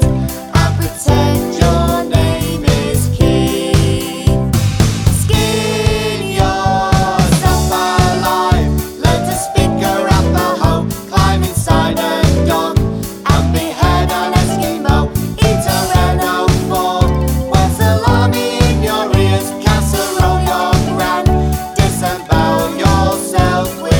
no Backing Vocals Comedy/Novelty 2:36 Buy £1.50